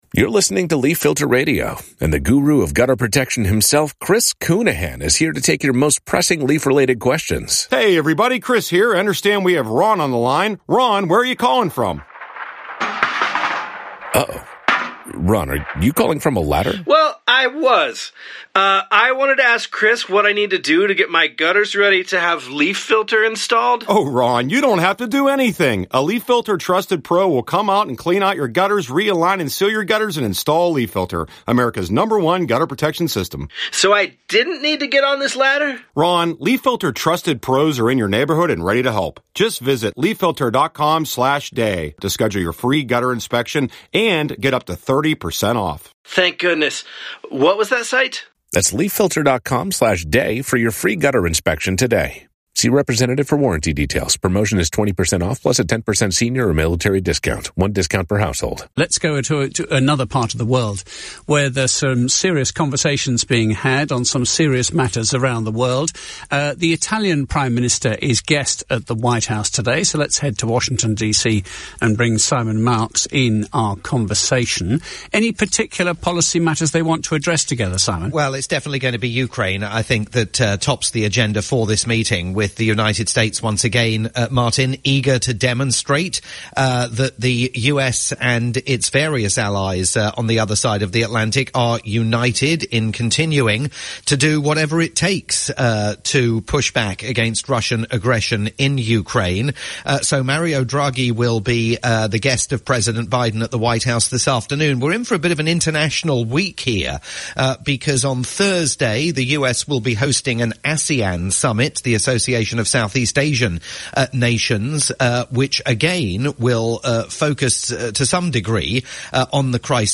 live update
lunchtime sequence on the UK's LBC News.